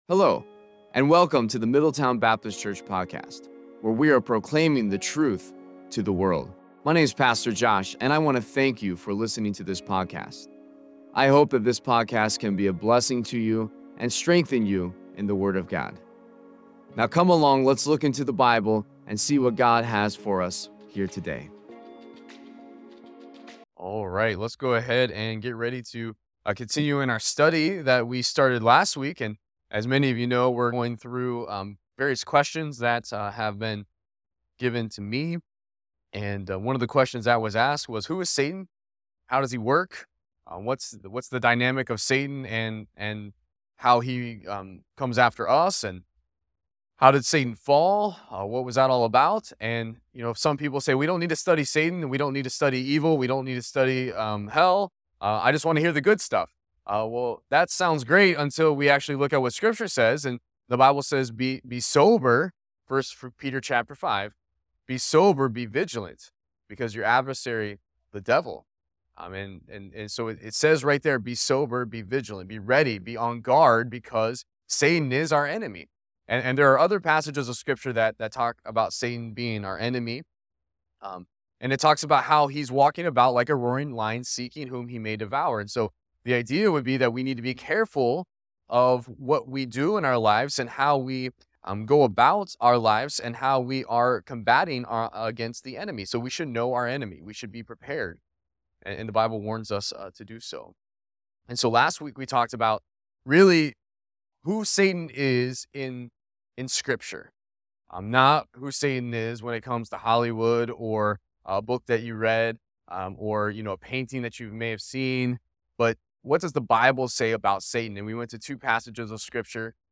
Worship-Service-April-2-2025.mp3